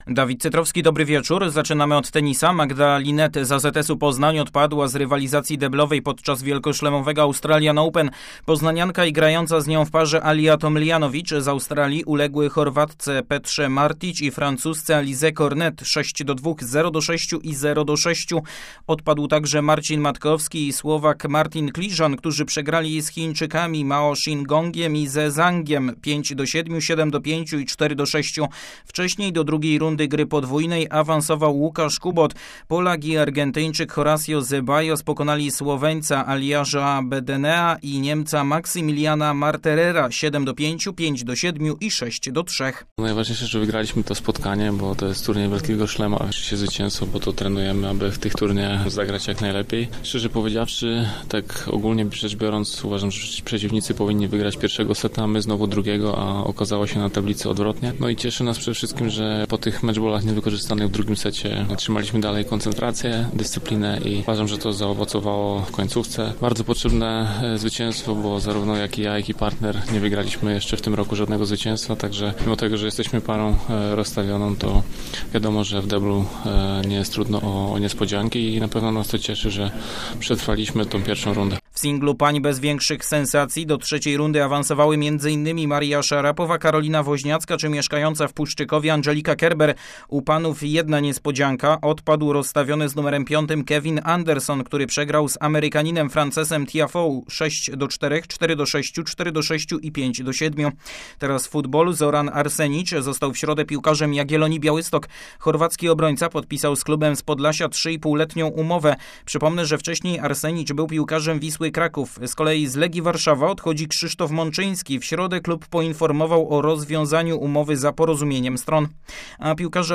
16.01. serwis sportowy godz. 19:05